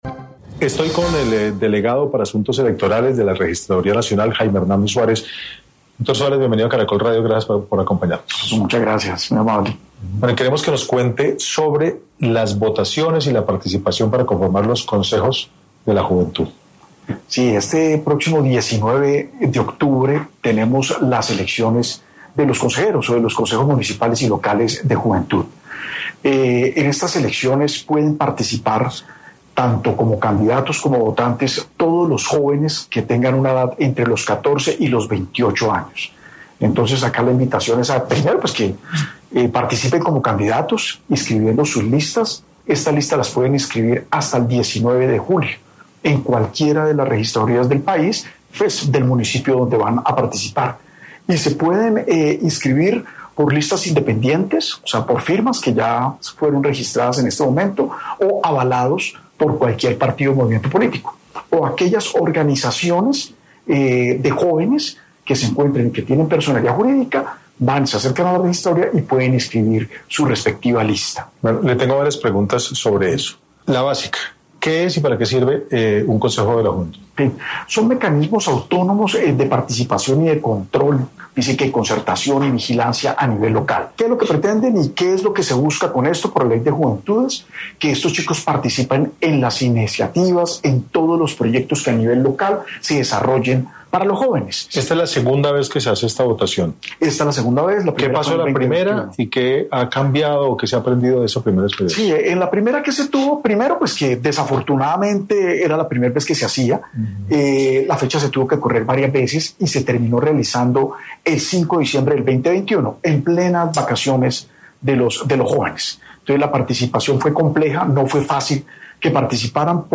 conversó con